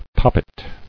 [pop·pet]